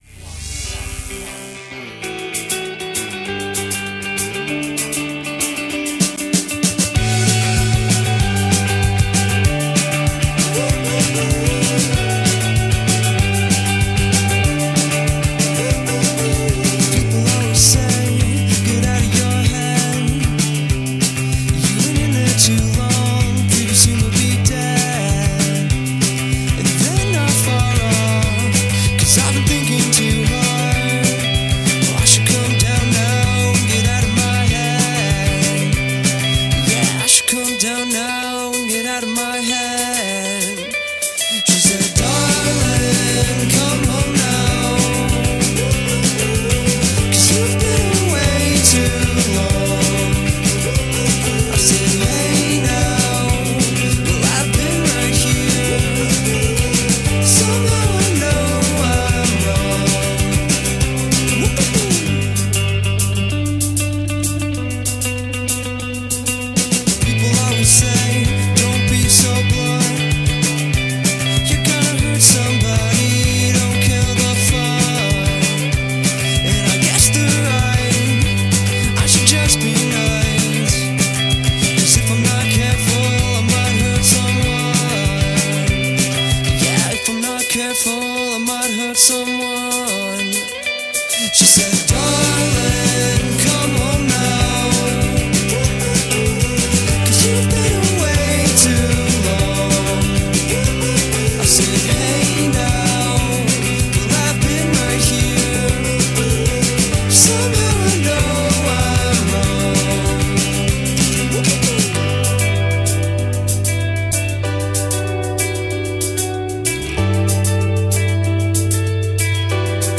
Recorded at Maida Vale Studios, London
a taste of Indie-Pop
Upbeat and frothy
American indie rock band